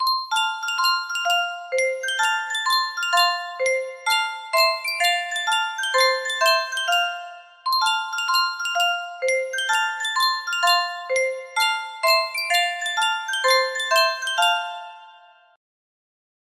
Sankyo Music Box - The Farmer in the Dell iB music box melody
Full range 60